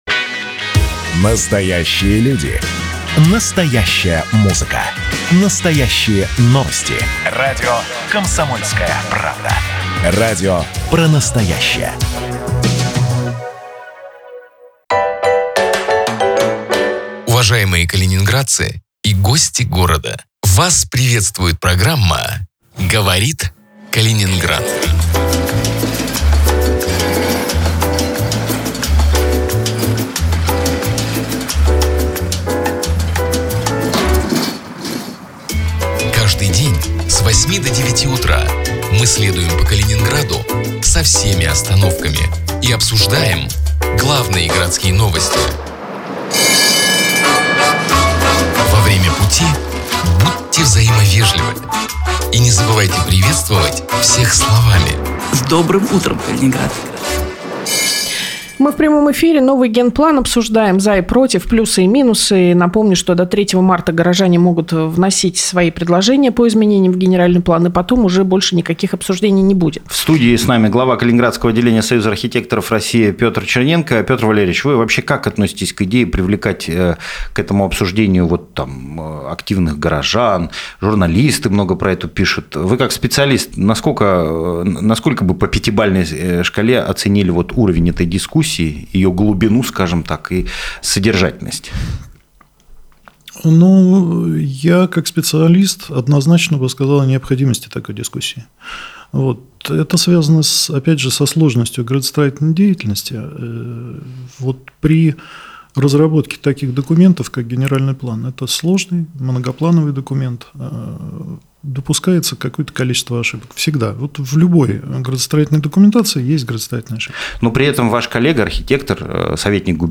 Каждое утро в прямом эфире обсуждаем городские новости.